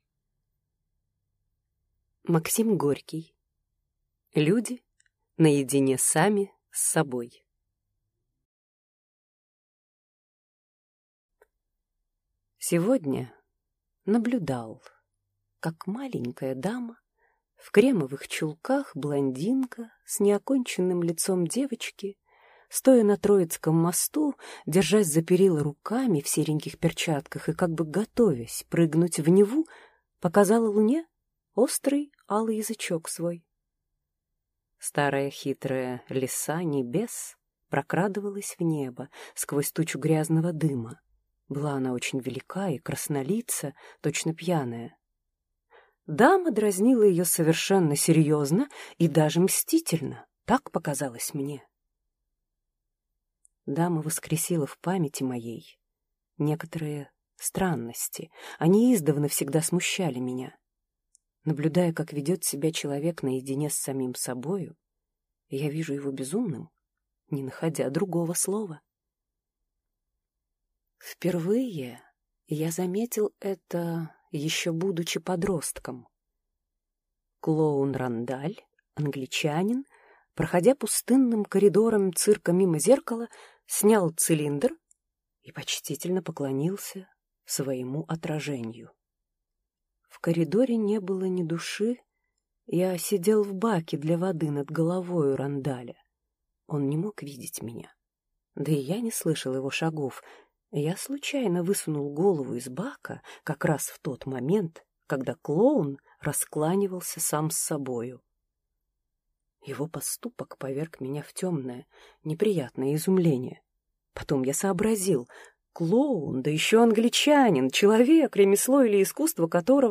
Аудиокнига Люди наедине сами с собой | Библиотека аудиокниг